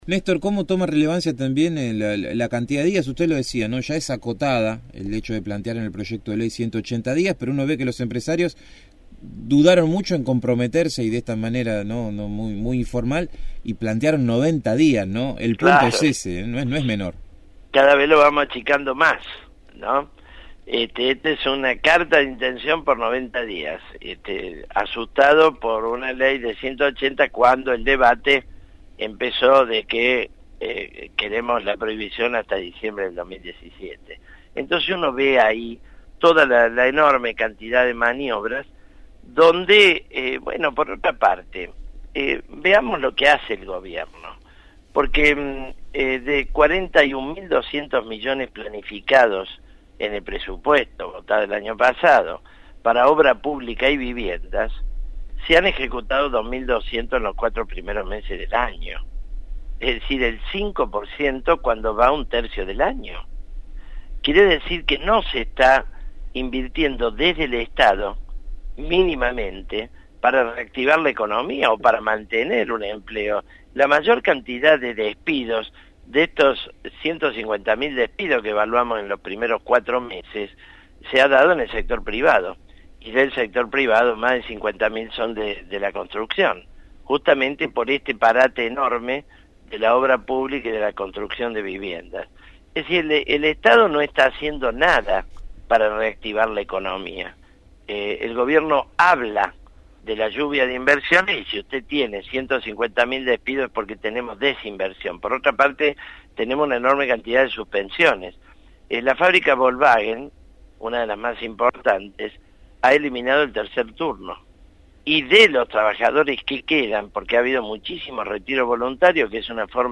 El diputado nacional por el Frente de Izquierda, Néstor Pitrola, dialogó con el equipo de “El Hormiguero” sobre el acuerdo de Mauricio Macri con un grupo de empresarios en relación a los despidos y al tratamiento de la ley «anti-despidos», a la cual piensa votar si se aprueba en el día de mañana en Diputados.